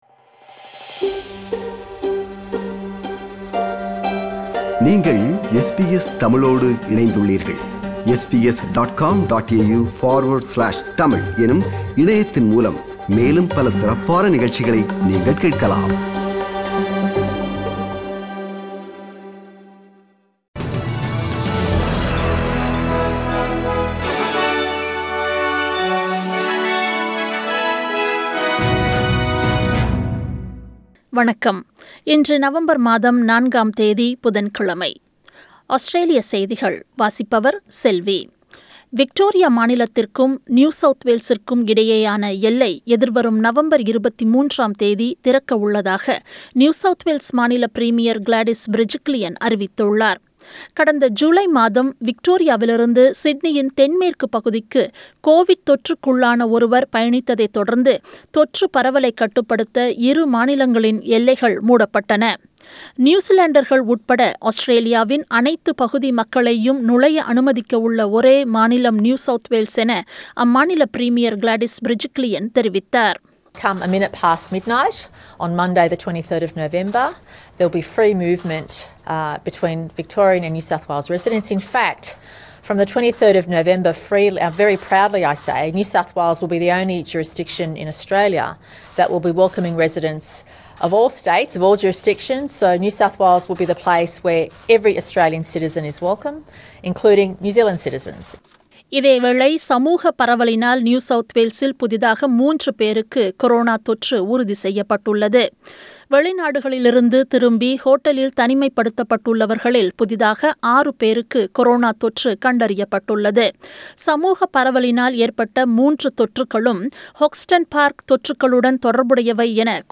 Australian news bulletin for Wednesday 04 November 2020.